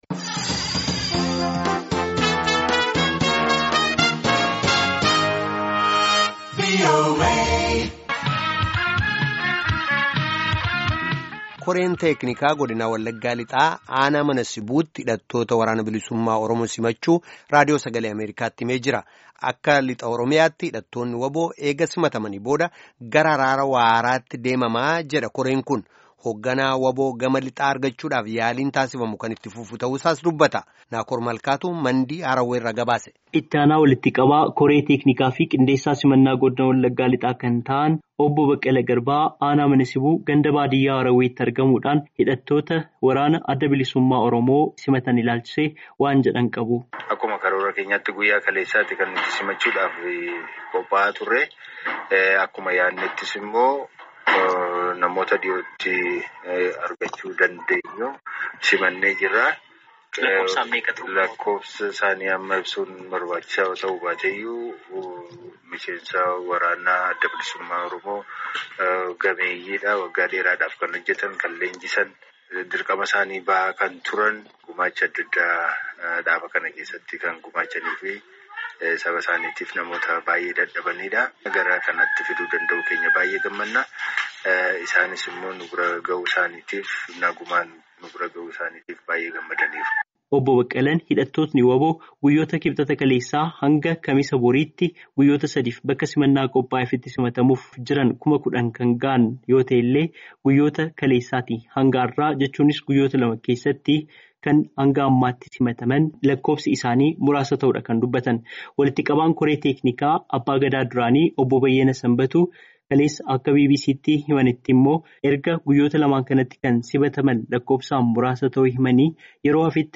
Gabaad simannaa WBO lixa wallaggaa caqasaa